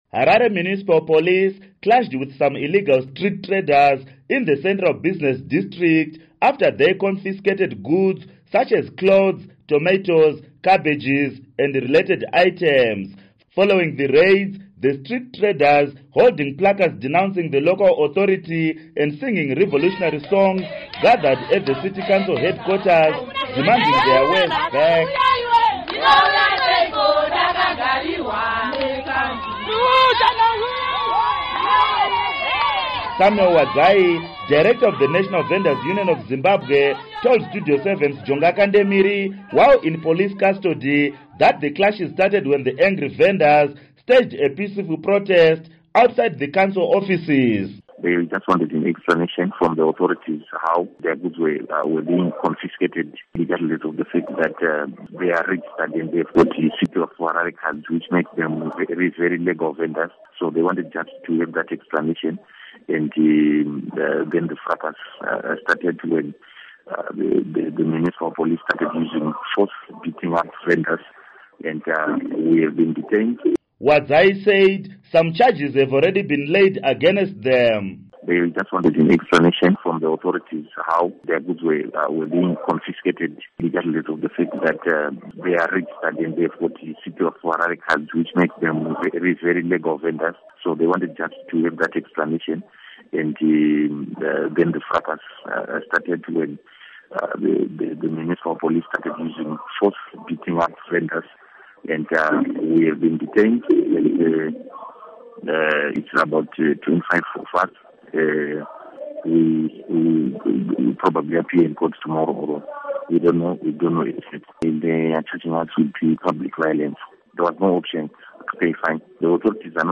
Report on Vendors Arrests